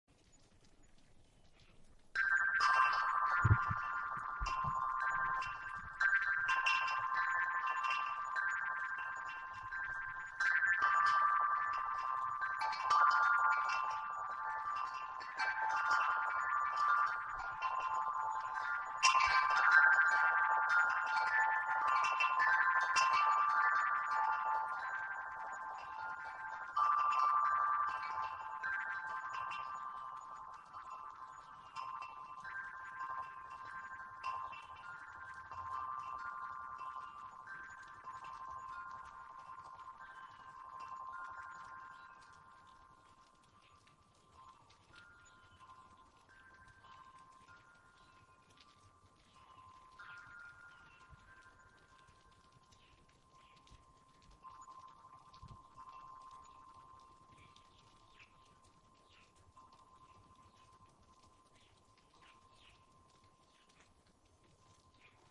Weird Bells 8 Sound Button - Free Download & Play